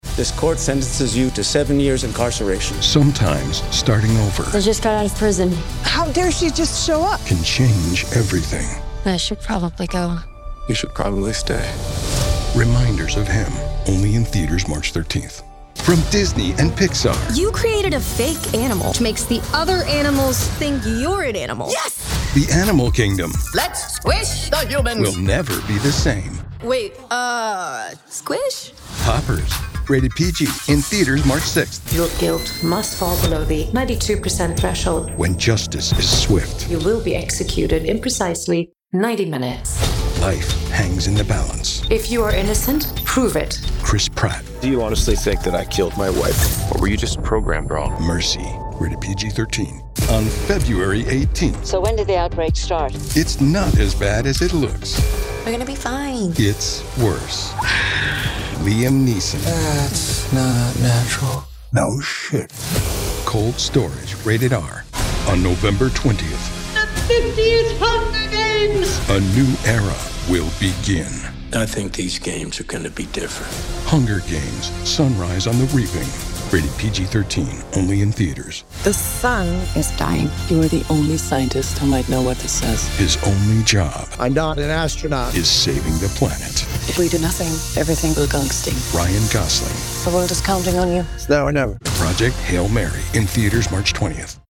Filmtrailer
Selbstsichere Gen X Männerstimme für Ihr Projekt – der lässige, gesprächige Ton für Werbespots, Unternehmensvideos, Dokus oder Erklärvideos.
Mikrofone: Sennheiser MKH416 & Neuman TLM103
Akustisch behandelte professionelle Aufnahmekabine